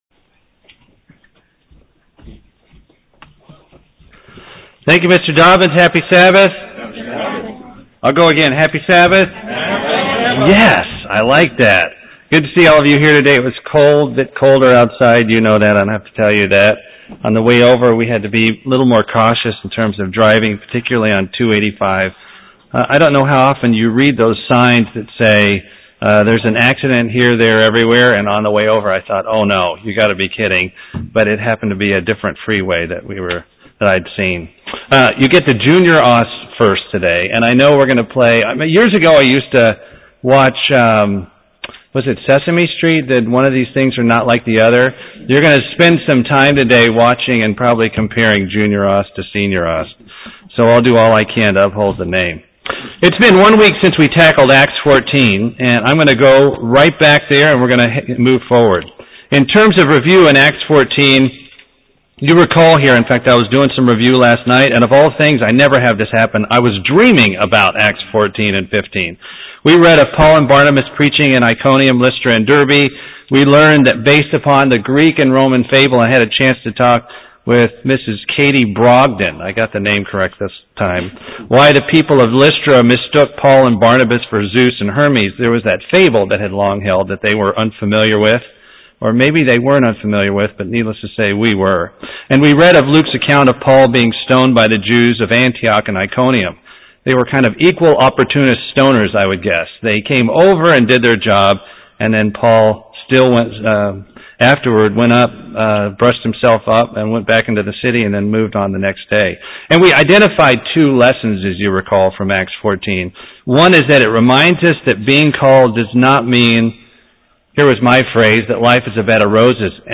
UCG Sermon Studying the bible?
Given in Buford, GA